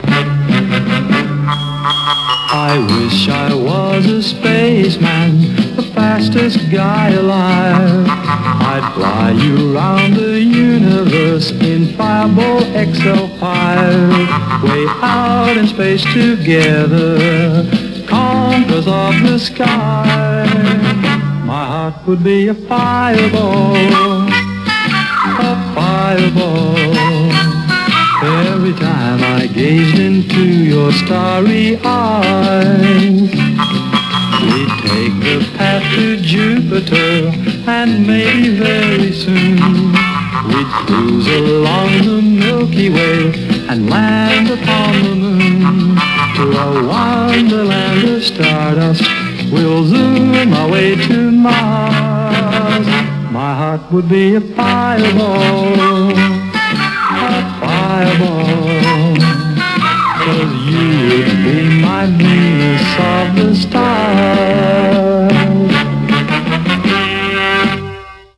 Closing Theme Desktop Theme Wallpaper #1